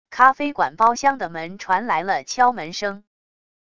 咖啡馆包厢的门传来了敲门声wav音频